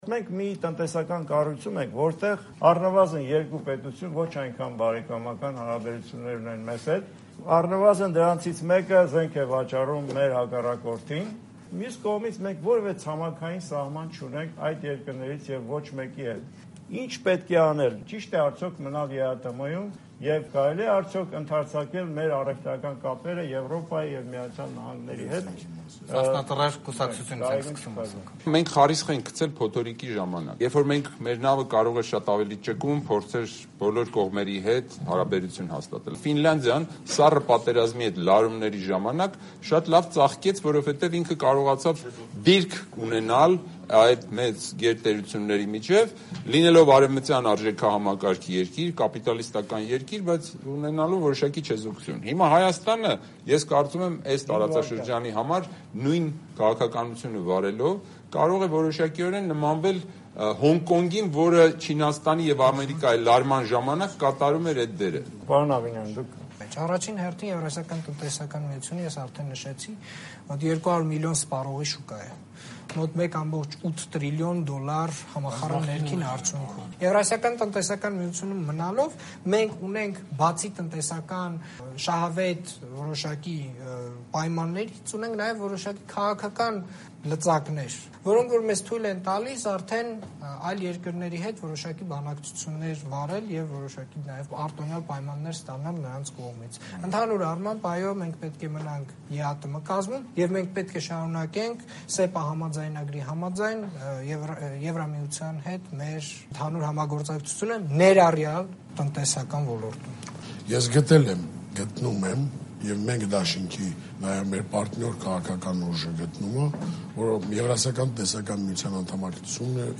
Ճիշտ է արդյո՞ք մնալ ԵԱՏՄ-ում. բանավեճ «Ազատության» եթերում